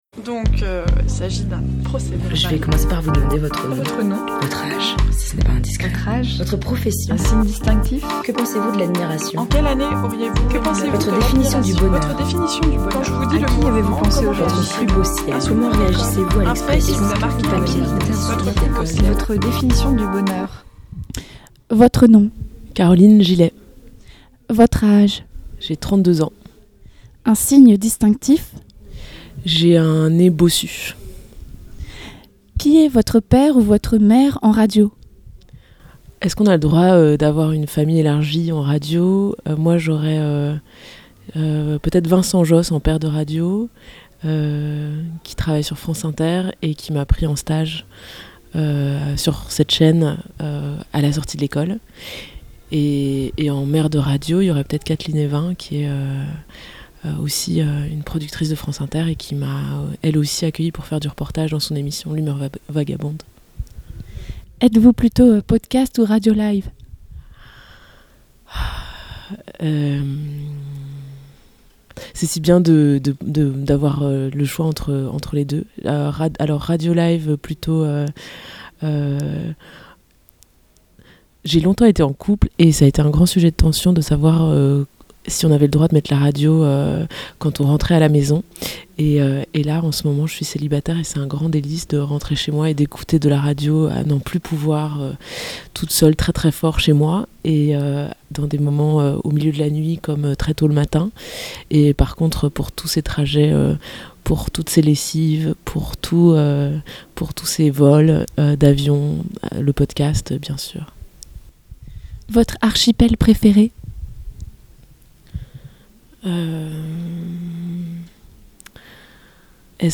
Festival Longueur d’ondes 2017
se pose des questions sur l’inconstance de la météo brestoise et chante avec talent par deux fois.